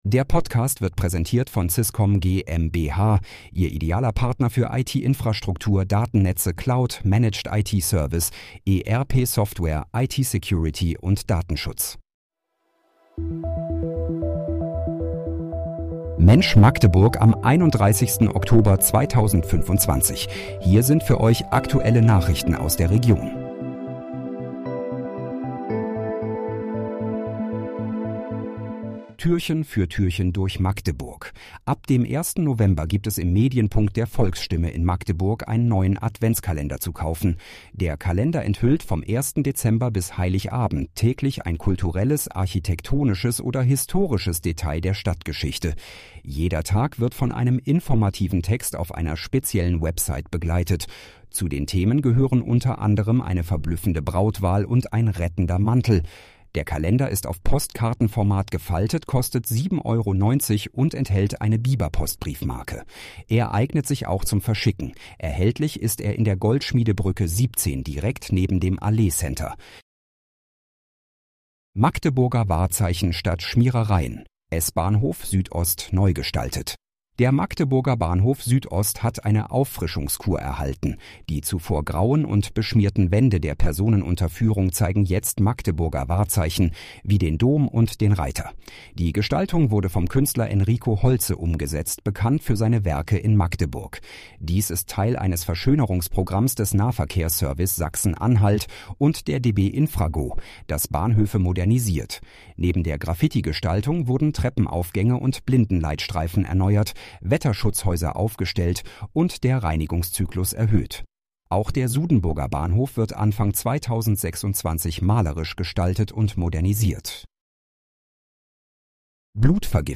Mensch, Magdeburg: Aktuelle Nachrichten vom 31.10.2025, erstellt mit KI-Unterstützung
Nachrichten